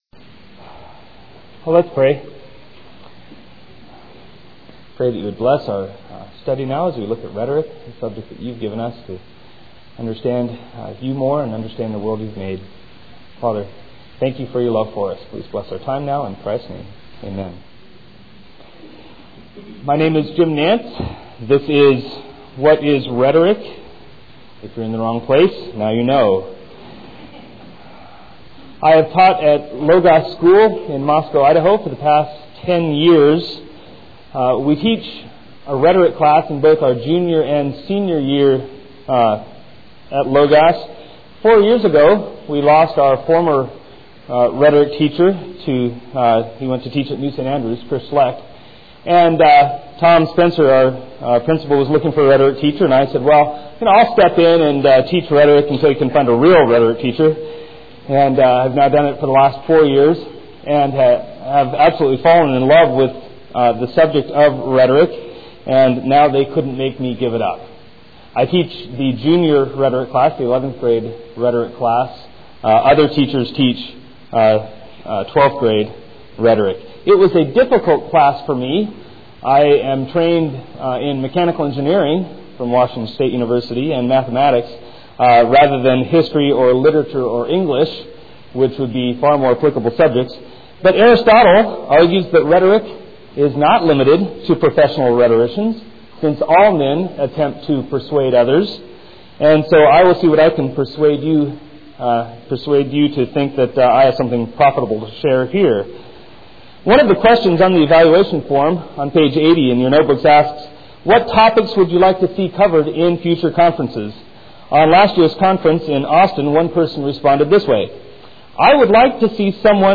2000 Workshop Talk | 0:52:44 | 7-12, Rhetoric & Composition